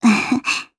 Gremory-Vox-Laugh_jp_b.wav